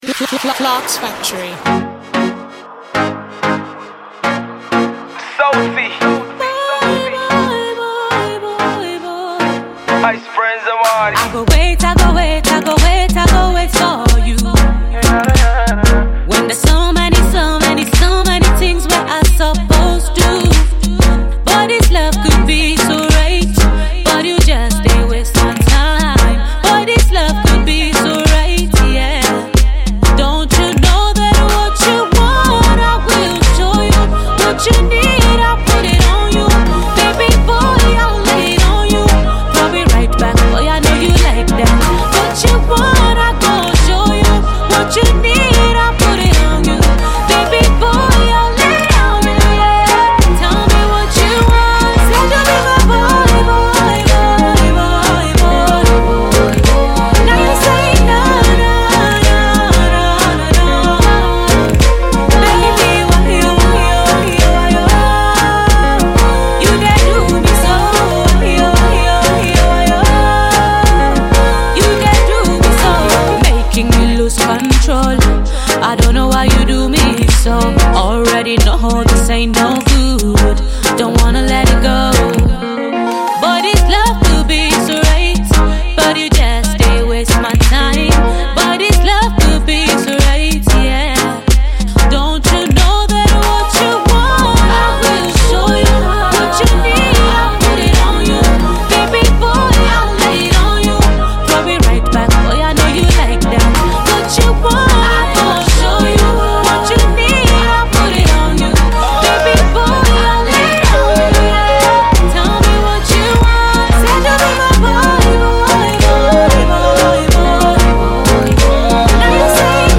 dancehall track